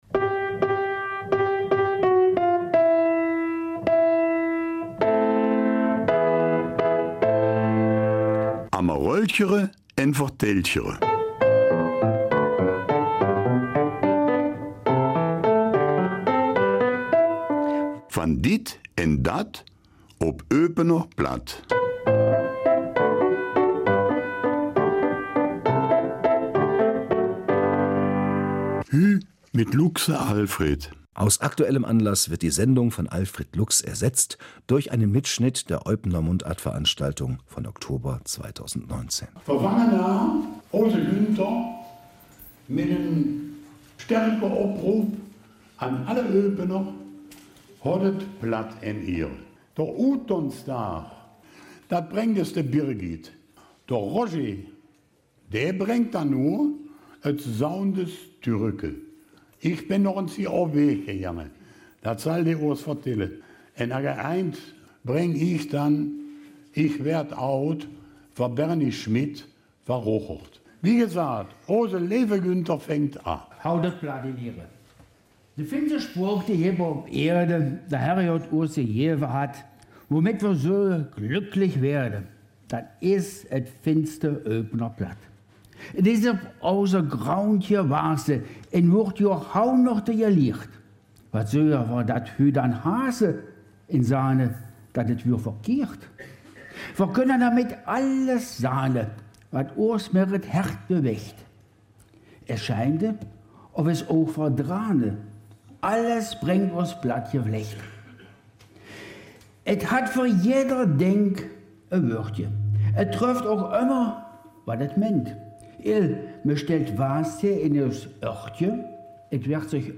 Mitschnitt der Eupener Mundartveranstaltung mit den Theaterfreunden und Plattvööt.